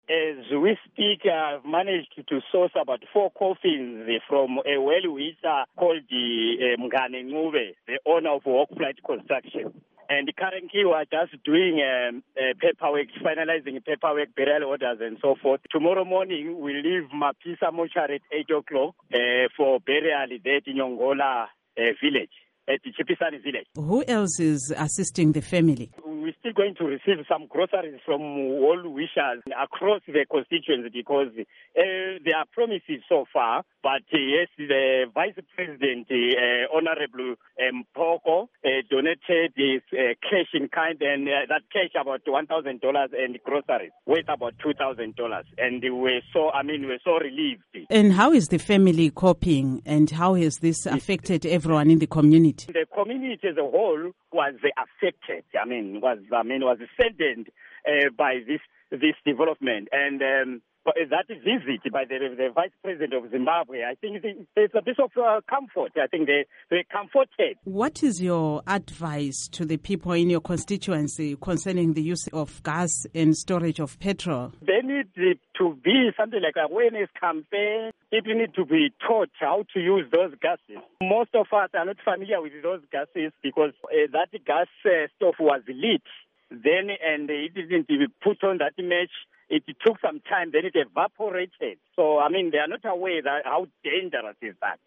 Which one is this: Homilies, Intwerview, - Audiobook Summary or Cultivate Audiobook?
Intwerview